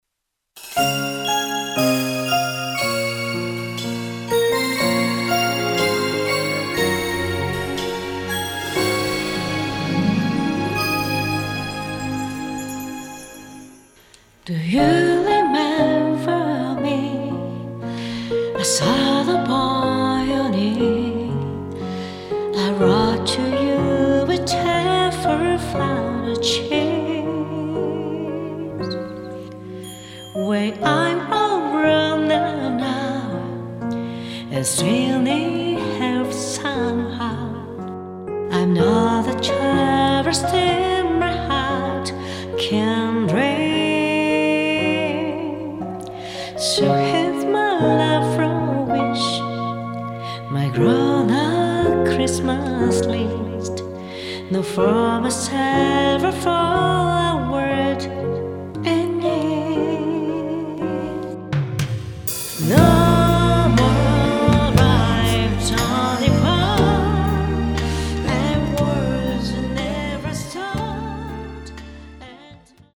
ボーカリスト担当